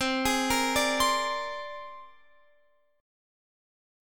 Listen to C7sus2#5 strummed